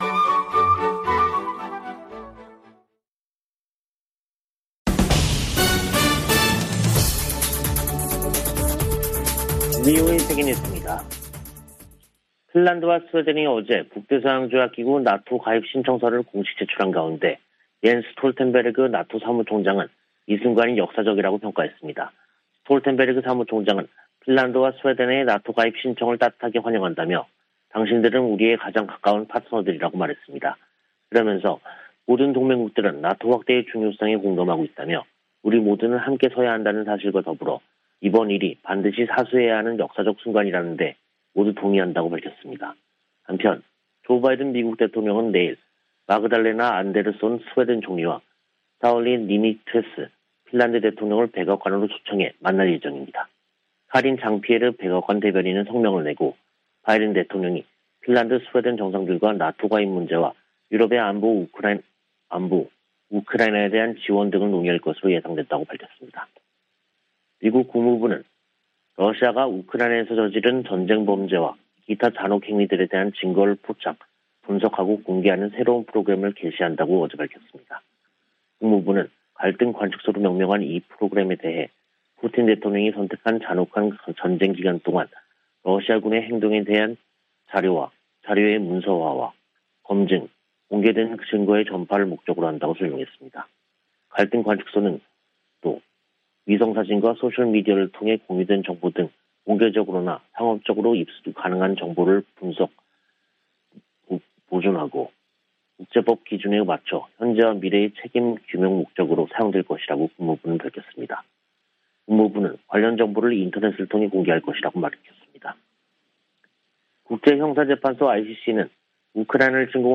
VOA 한국어 간판 뉴스 프로그램 '뉴스 투데이', 2022년 5월 18일 2부 방송입니다. 오는 21일 서울에서 열리는 미한 정상회담에서 북한 핵 위협에 대한 실효적인 확장억제력 강화 방안이 핵심적으로 다뤄질 것이라고 한국 대통령실이 밝혔습니다. 미 국무부는 북한이 코로나바이러스 감염증 확산세 속에서도 핵실험 의지를 꺾지 않을 것이라고 내다봤습니다. 미 의회에 발의된 북한 인권법 연장 법안에 코로나 관련 지원 기조가 처음으로 명기됐습니다.